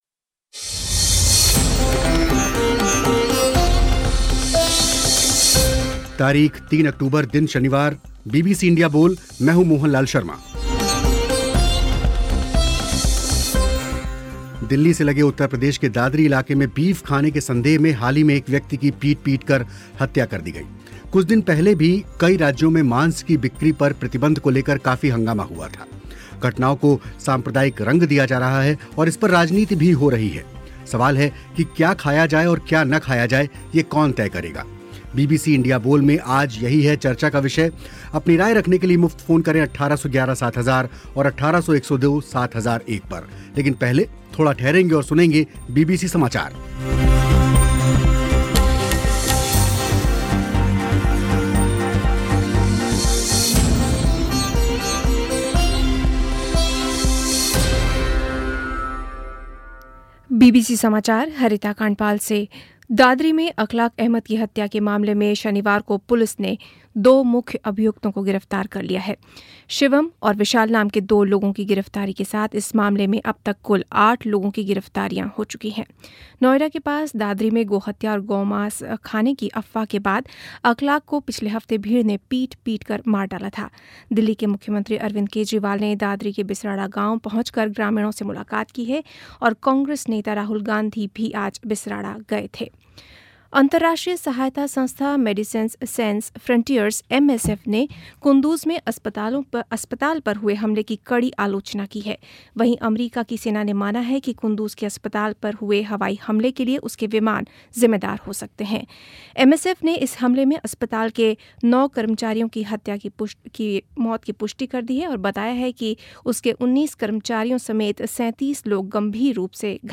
सवाल है कि क्या खाया जाए और क्या न खाया जाए ये कौन तय करेगा ? कार्यक्रम में इसी विषय पर चर्चा हुई, हिस्सा लिया विश्लेषक प्रोफेसर पुष्पेश पंत और आप सब श्रोताओं ने